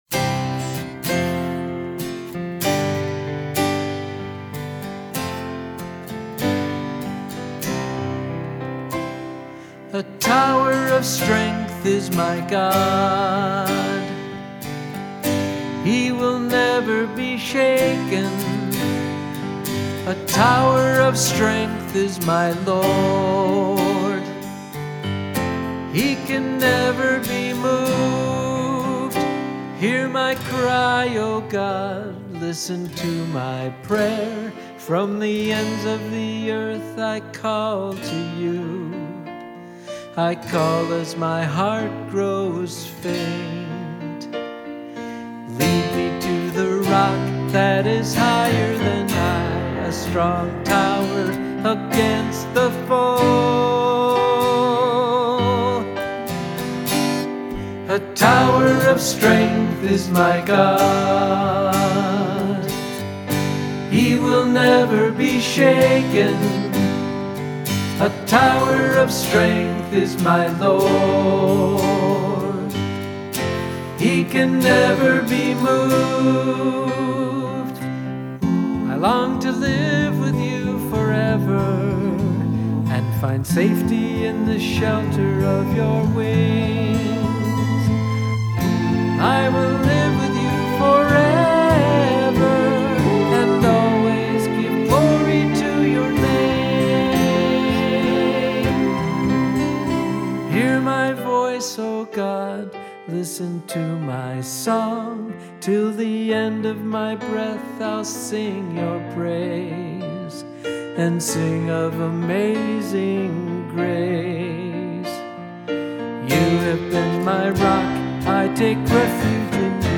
Recorded in 2019, Ithaca and Minneapolis
Keyboards
Guitars
Vocals
Bagpipes